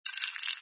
sfx_ice_moving08.wav